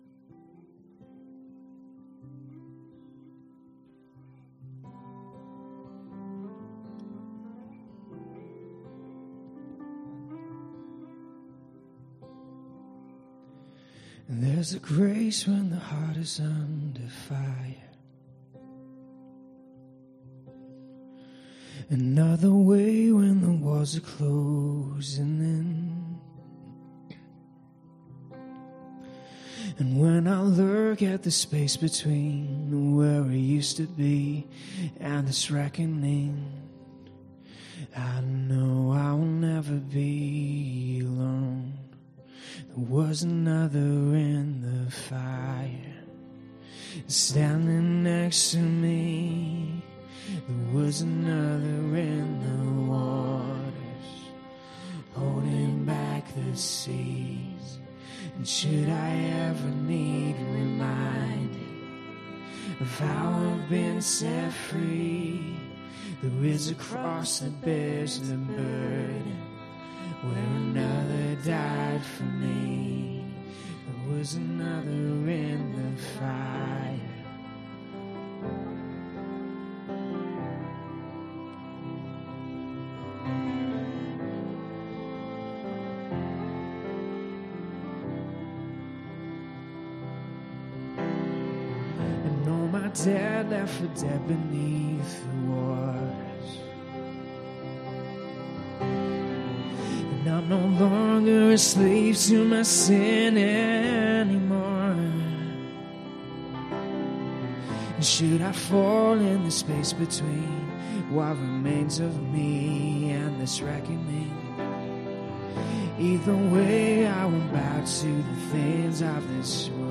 Ministry Song
Service Type: pm